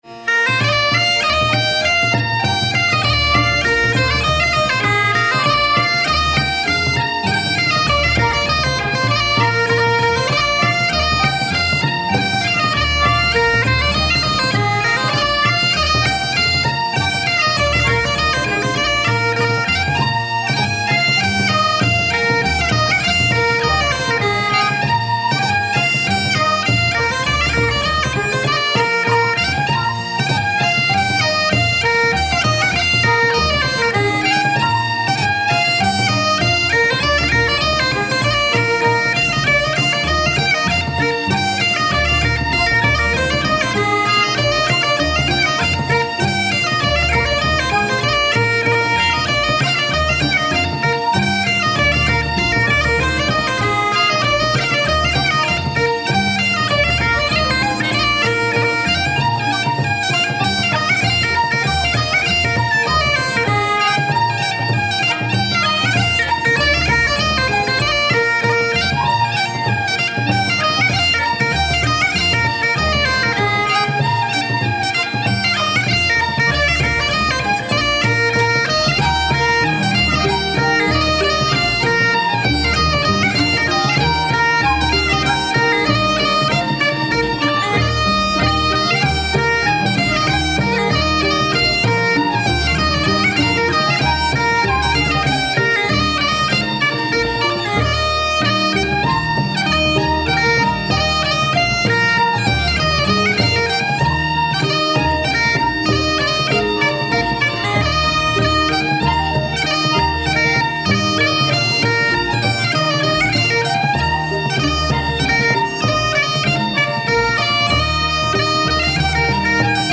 Two quickstep marches